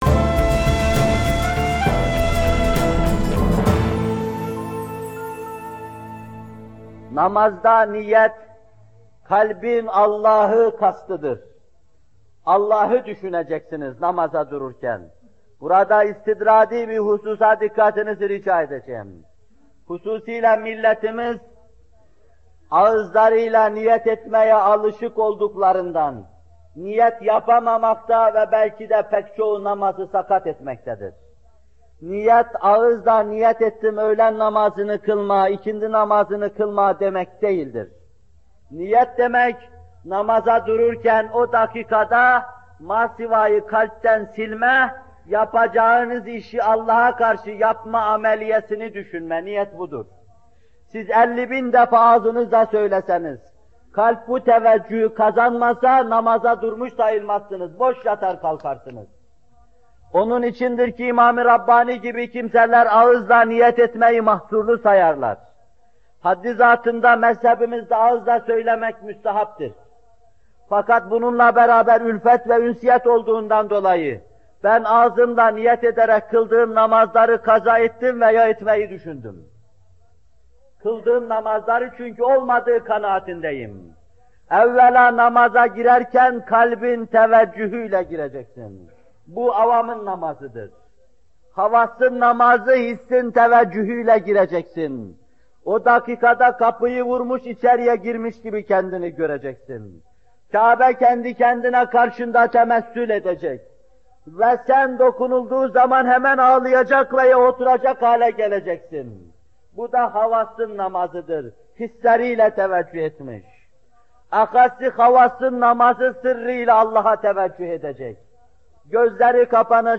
Bu bölüm Muhterem Fethullah Gülen Hocaefendi’nin 22 Eylül 1978 tarihinde Bornova/İZMİR’de vermiş olduğu “Namaz Vaazları 6” isimli vaazından alınmıştır.